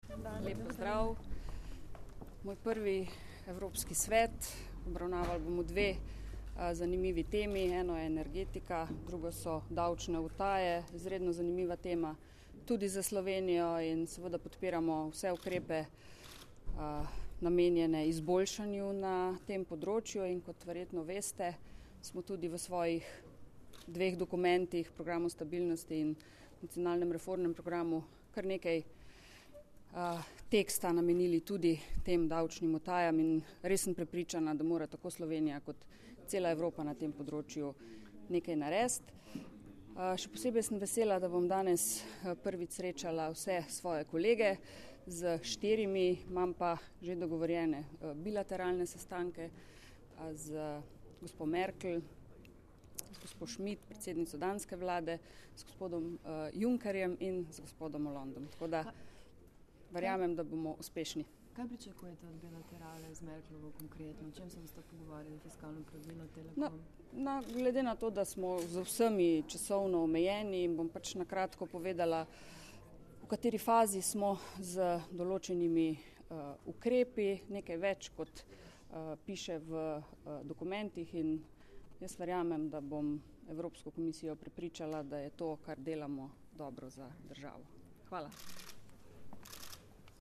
Ob prihodu na sedež Evropskega sveta je predsednica vlade v izjavi za medije dejala, da verjame, da bo sogovornike prepričala, da je to, kar delamo, dobro za Slovenijo. Glede same vsebine današnjega zasedanja pa je poudarila tudi, da morata tako Slovenija kot vsa Evropa odločno ukrepati proti vsem vrstam davčnih utaj.